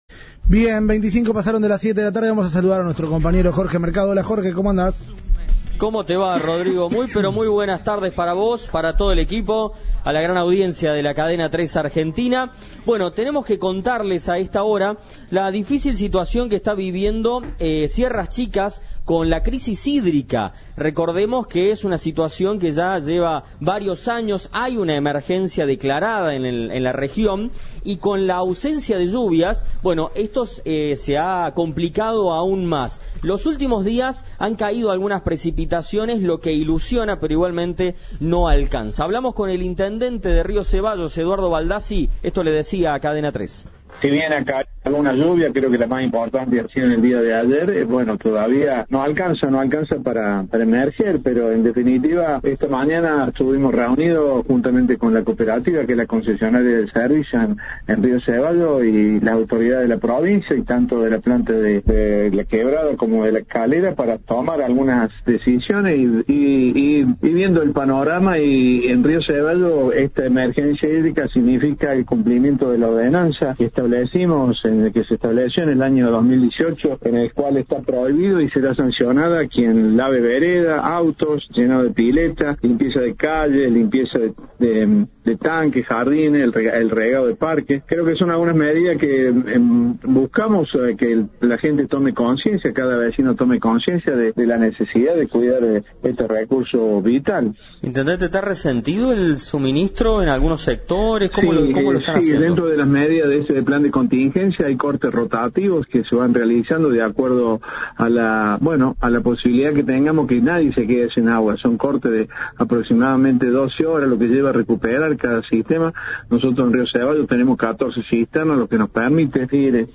Eduardo Baldassi, intendente de Río Ceballos, dijo a Cadena 3 que las recientes lluvias no alcanzan para solucionar el problema.
Informe